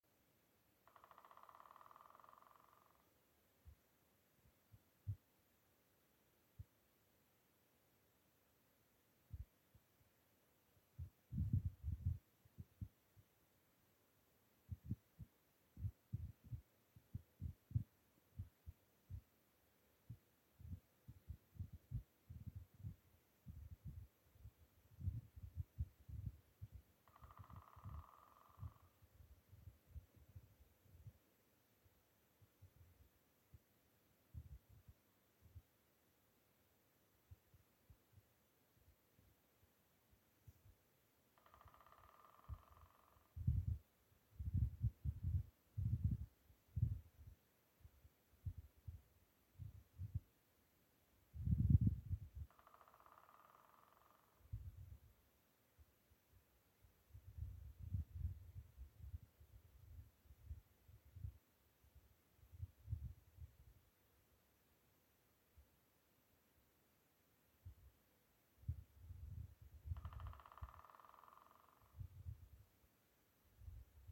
White-backed Woodpecker, Dendrocopos leucotos
Notes/Bungo